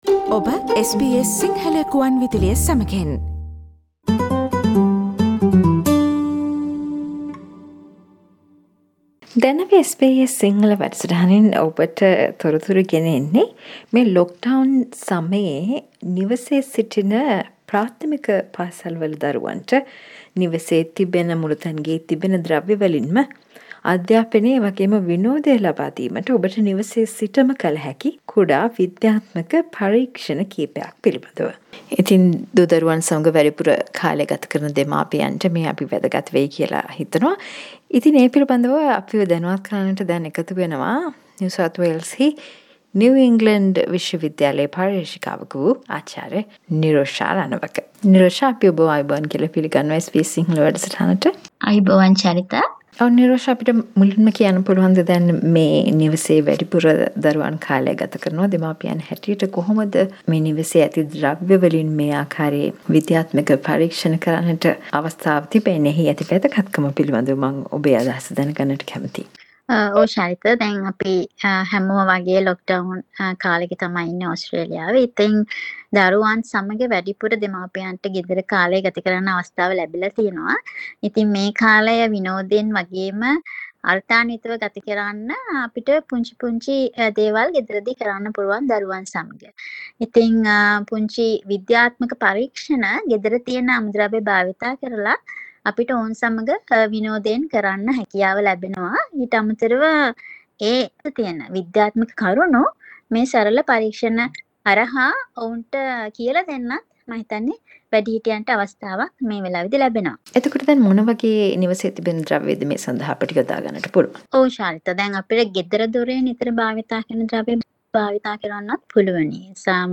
ඔබේ දරුවාට කල හැකි සරල ක්‍රියාකාරකම් පිළිබද සාකච්චාවක්